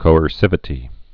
(kōər-sĭvĭ-tē)